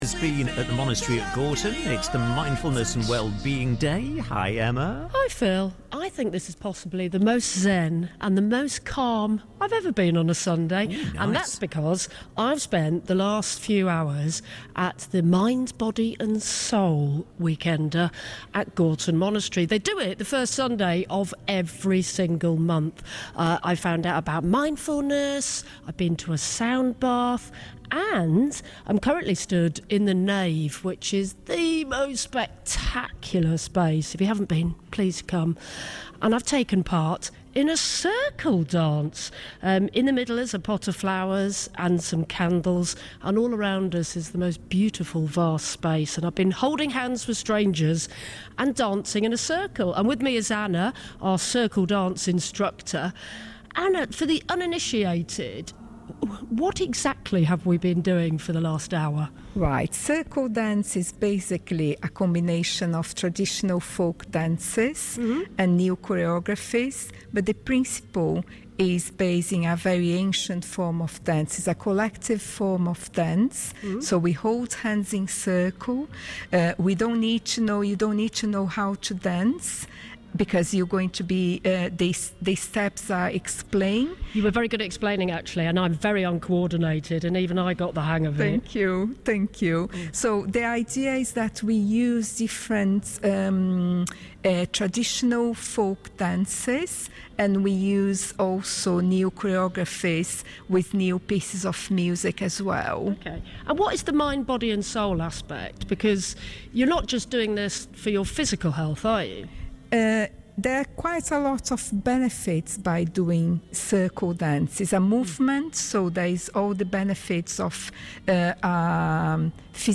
interviewed by Radio Manchester about the therapeutic benefits of the ancient practice of Circle Dance